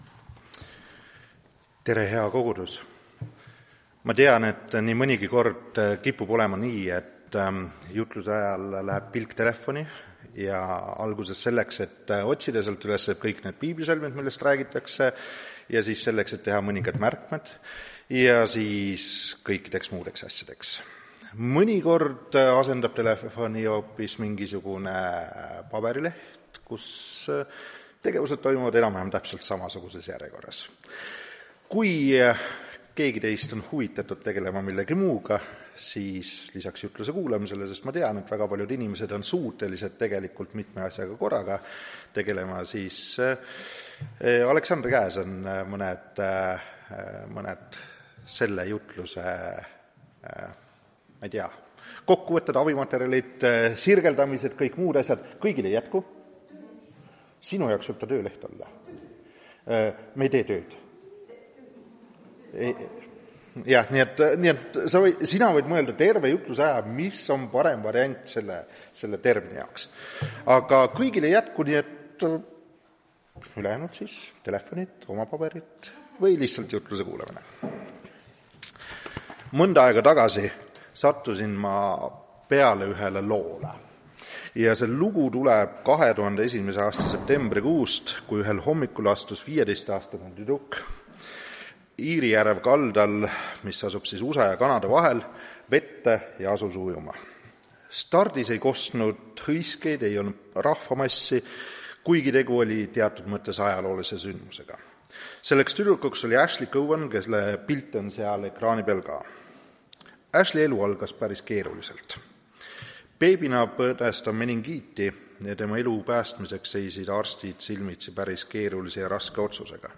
Tartu adventkoguduse 03.01.2026 teenistuse jutluse helisalvestis.